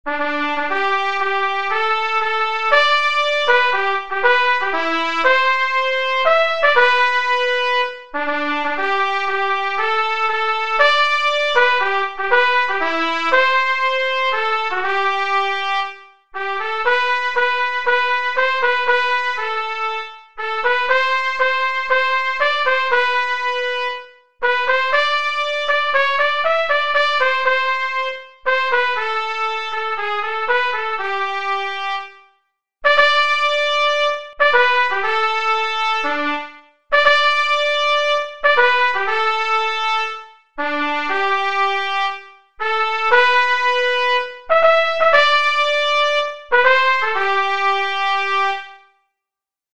La Citoyenne, Solo, Instruments